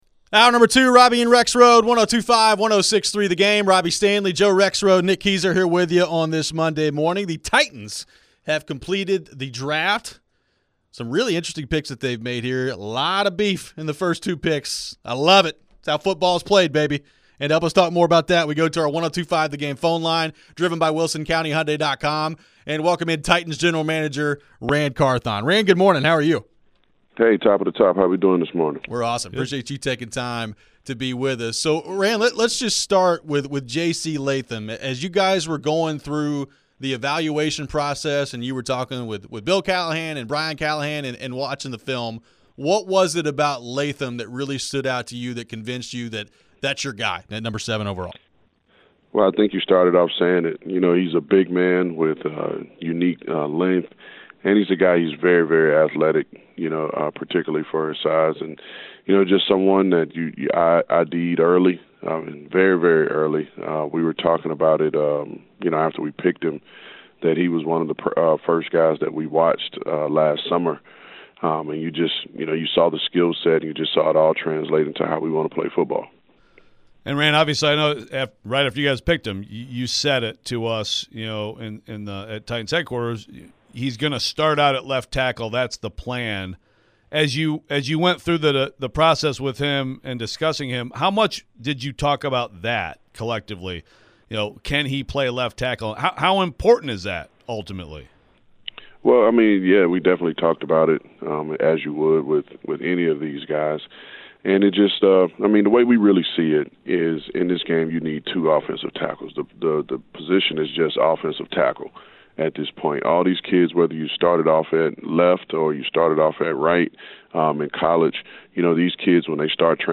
Ran Carthon Interview (4-29-24)
Tennessee Titans General Manager Ran Carthon joined the show after his second draft with the franchise.